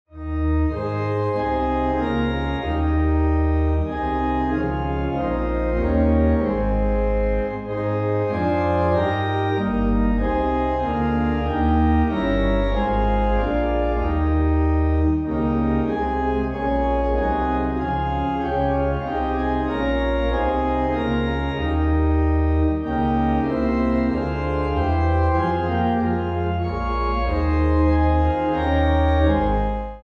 Organ
Ab